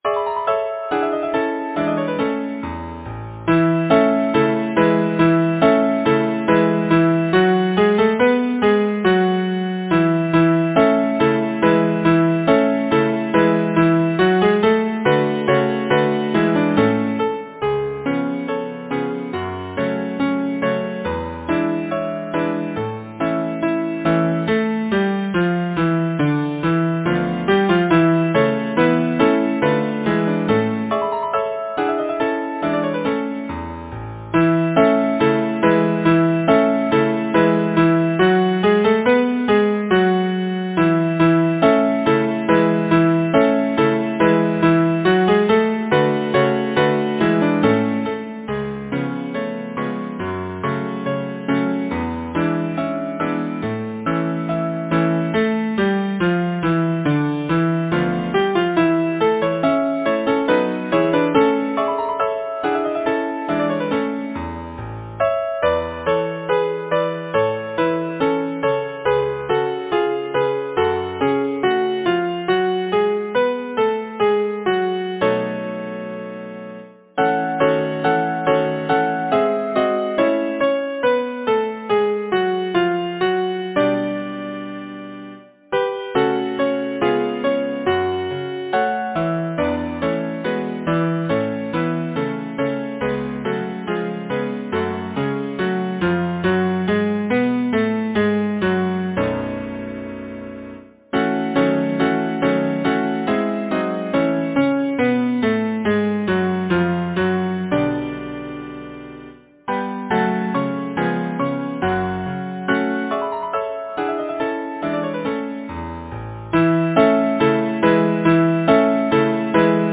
Title: The Wooing Composer: Louis A. Coerne Lyricist: Paul Laurence Dunbar Number of voices: 4vv Voicing: SATB Genre: Secular, Partsong
Language: English Instruments: Piano